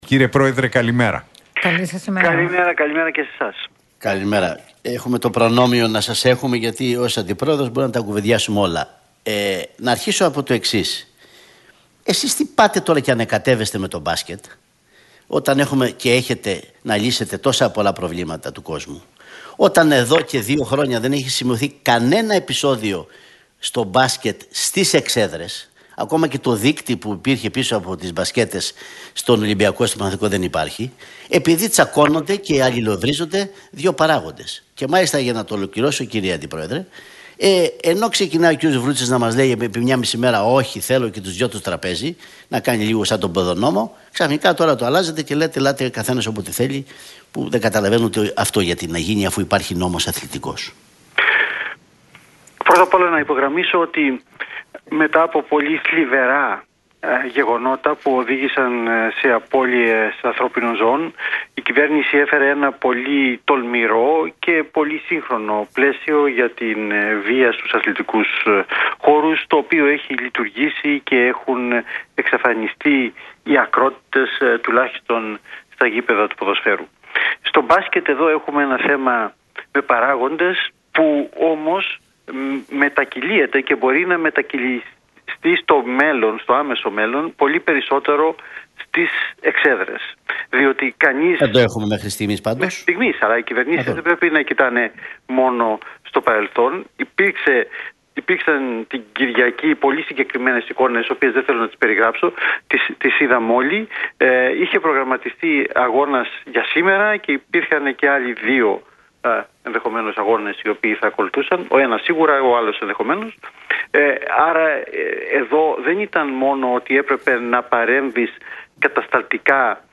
Χατζηδάκης στον Realfm 97,8: Στέλνουμε ένα μήνυμα κυρίως στους παράγοντες - Εάν δεν δεχτούν το πλαίσιο, θα σταματήσει το πρωτάθλημα